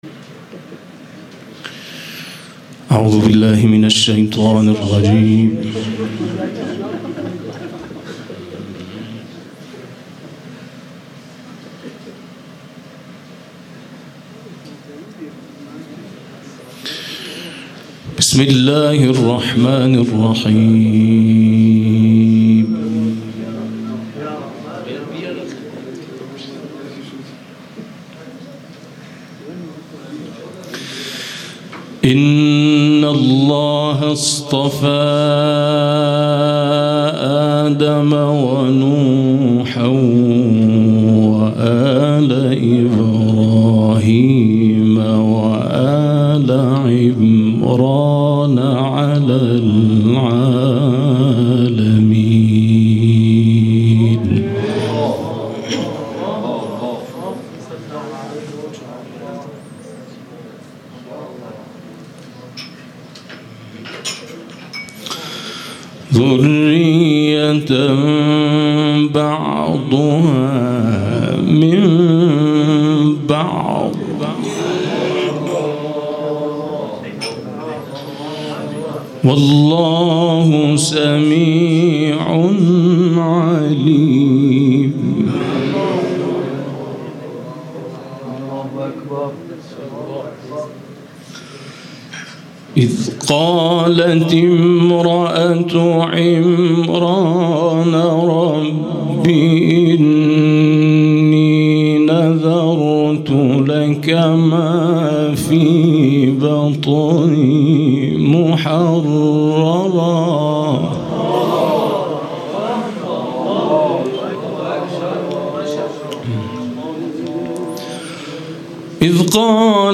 احمد ابوالقاسمی در مجمع قرآنیان اسلامشهر ضمن تأکید بر اهمیت موضوعیت داشتن تلاوت قرآن بیان کرد: اینکه مقام معظم رهبری نسبت به نَفس تلاوت قرآن و جلساتی ویژه تلاوت تأکید دارند برای این است که تلاوت موضوعیت دارد و وقتی موضوعیت آن درک شد، در یک بازه زمانی بلندمدت می‌توان اثرات آن را دید که عبارت از تشکیل یک جامعه قرآنی است.